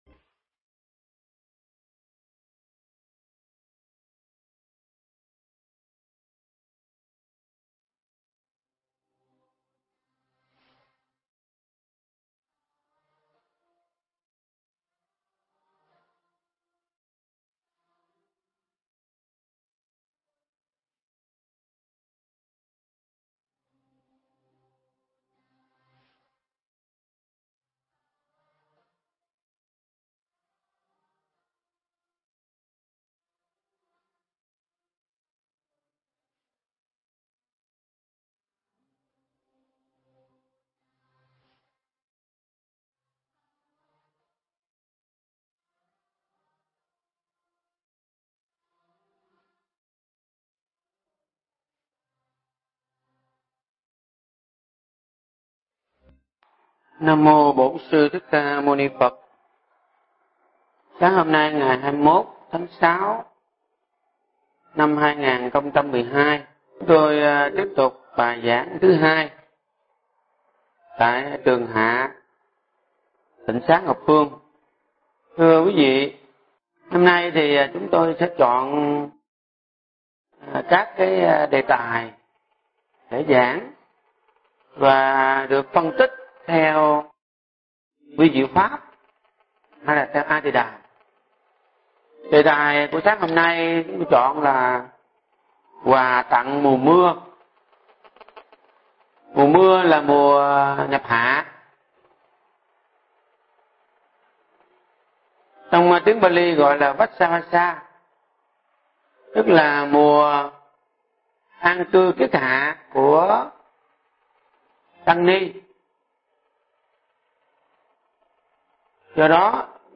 Nghe Mp3 thuyết pháp Quà Tặng Mùa Mưa